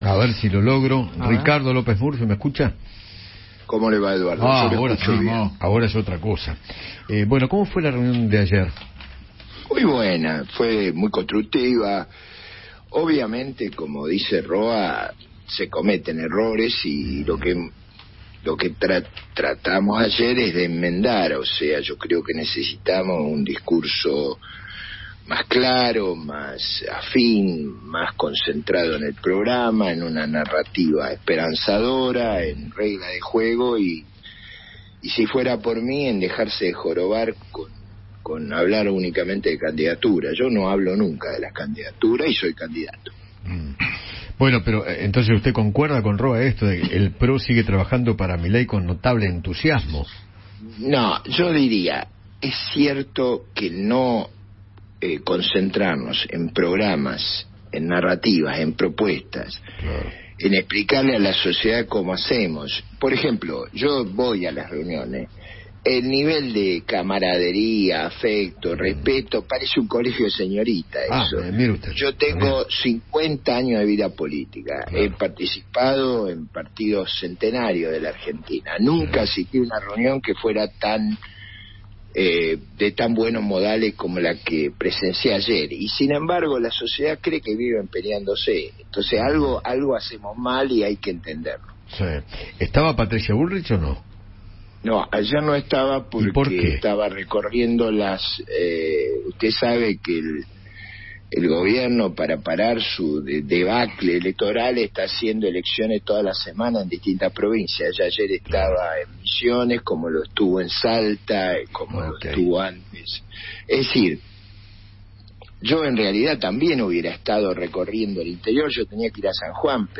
Eduardo Feinmann conversó con Ricardo López Murphy sobre la crisis del país y se refirió a las próximas elecciones.